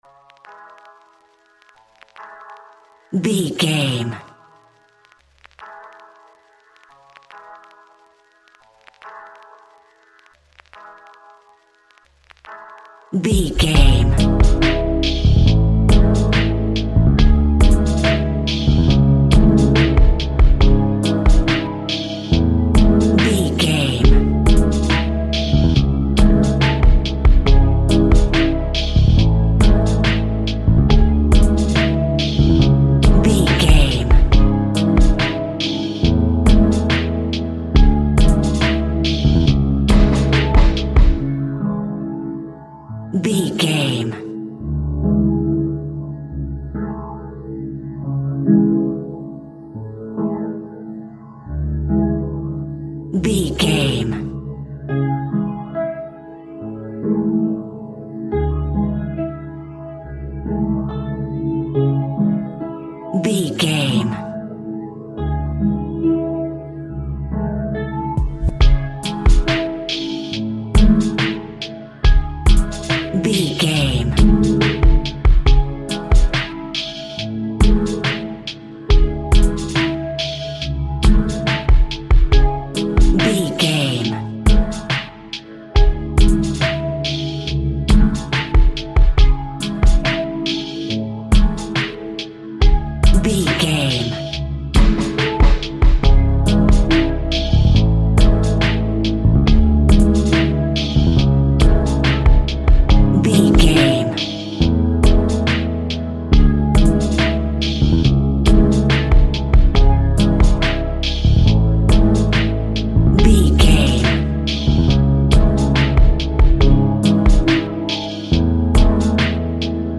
Sad and Somber Hip Hop Beat.
Aeolian/Minor
C#
Slow
chilled
laid back
hip hop drums
hip hop synths
piano
hip hop pads